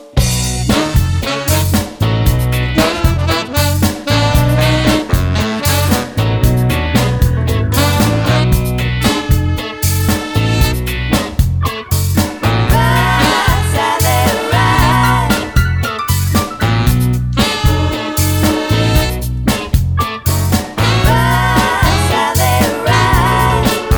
One Semitone Down Soundtracks 4:00 Buy £1.50